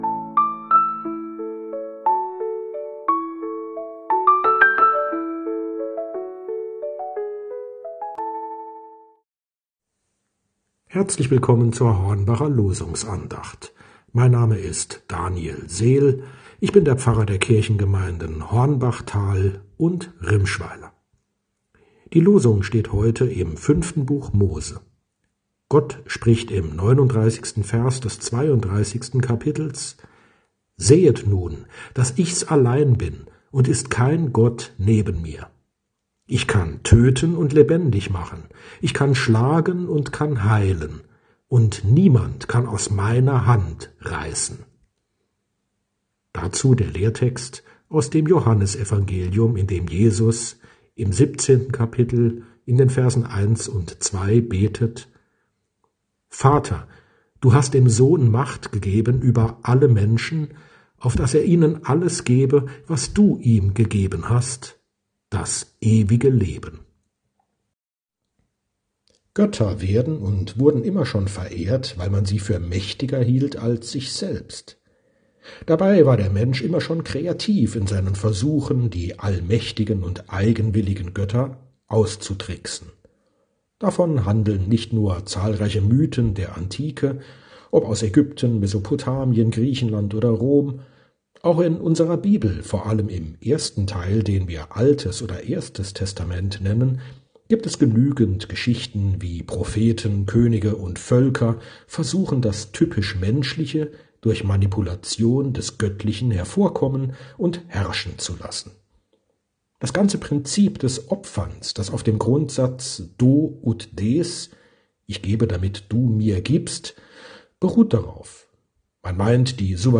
Losungsandachten